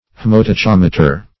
Search Result for " haemotachometer" : The Collaborative International Dictionary of English v.0.48: Haemotachometer \H[ae]m`o*ta*chom"e*ter\ (-t[.a]*k[o^]m"[-e]*t[~e]r), n. Same as H[ae]matachometer .